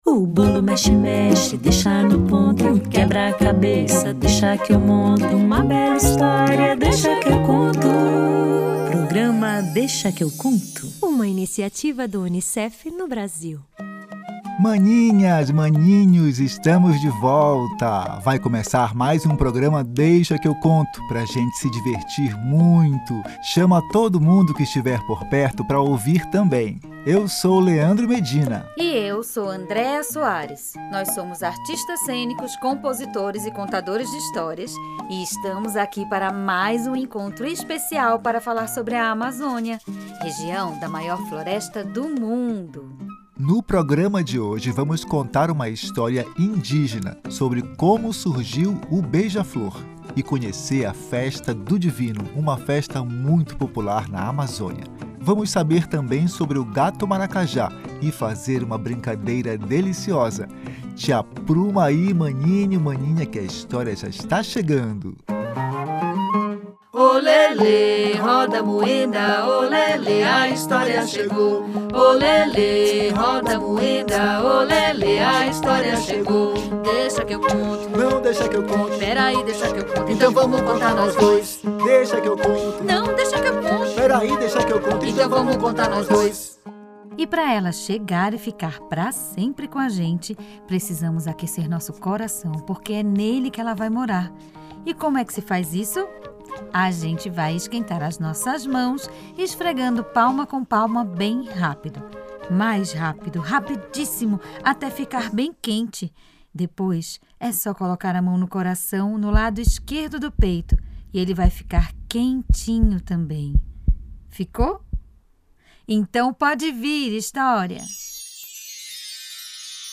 vão contar a história de uma índia chamada Potyra e de como surgiu o pássaro beija-flor. Vamos conhecer a Festa do Divino Espírito Santo, que existe na Amazônia, saber um pouco sobre o gato maracajá e brincar com a cor azul.